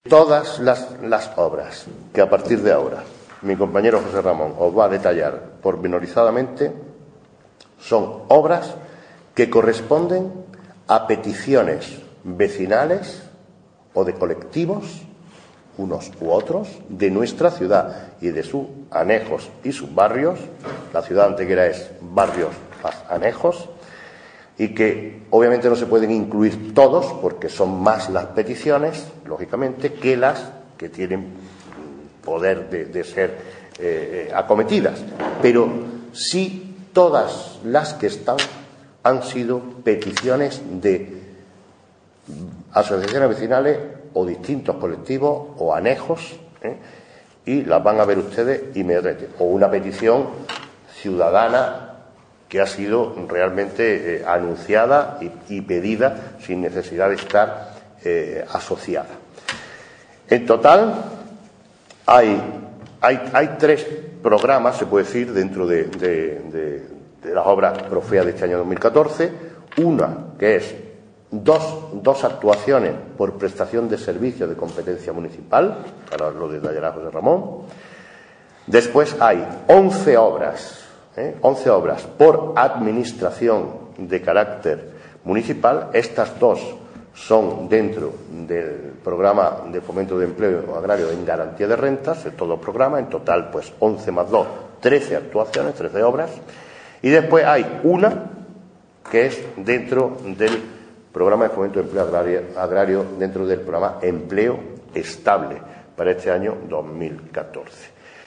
El alcalde de Antequera, Manolo Barón, y el teniente de alcalde de Urbanismo y Desarrollo Industrial, José Ramón Carmona, han informado en la mañana de hoy a los medios de comunicación sobre las obras a incluir dentro del Programa de Fomento del Empleo Agrario 2014 tanto en su modalidad de garantía de rentas como en la de empleo estable.
Cortes de voz